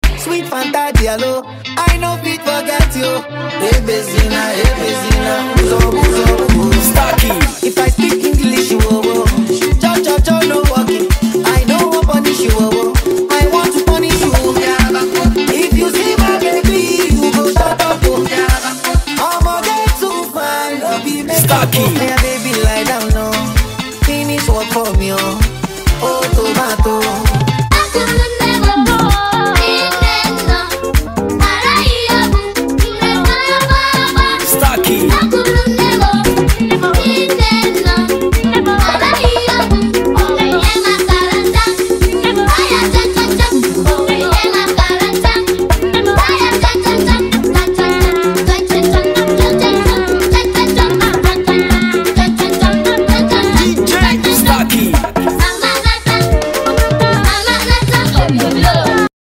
electrifying Afrobeat experience
powerful vocal contributions